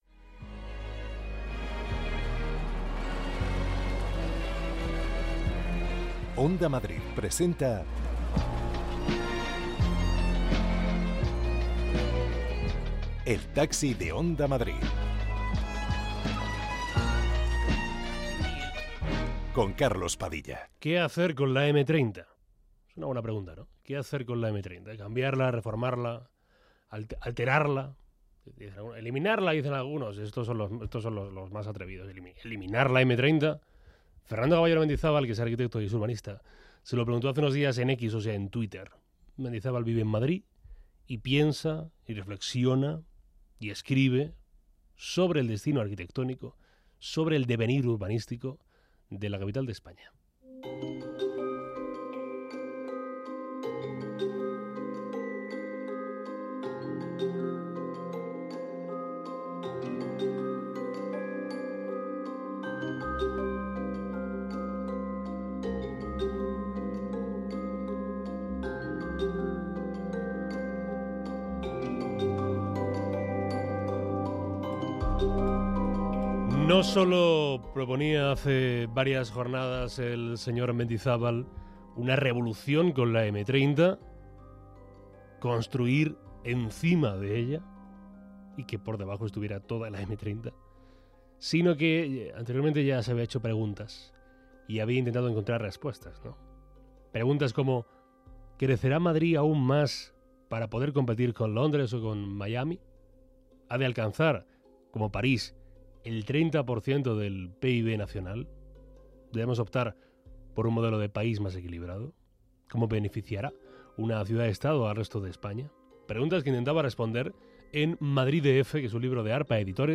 Conversaciones para escapar del ruido.